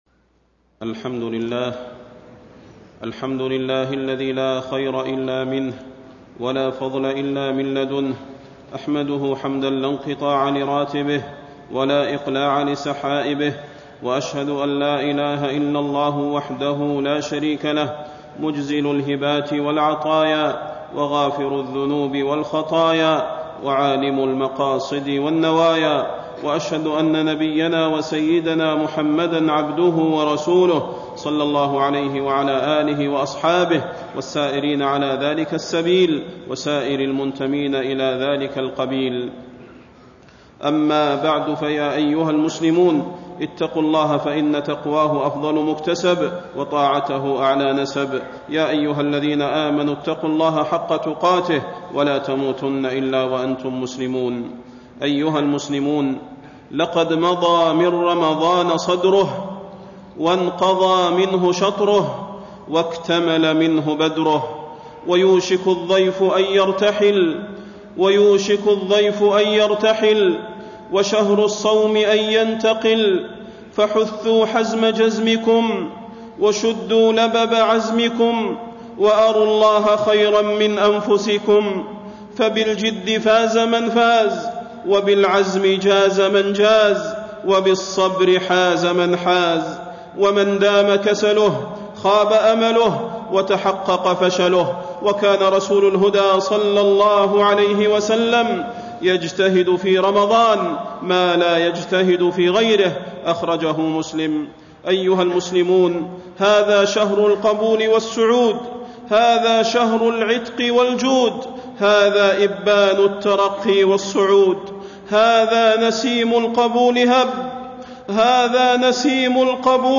تاريخ النشر ١٥ رمضان ١٤٣٣ هـ المكان: المسجد النبوي الشيخ: فضيلة الشيخ د. صلاح بن محمد البدير فضيلة الشيخ د. صلاح بن محمد البدير قرب رحيل رمضان